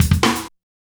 drums short02.wav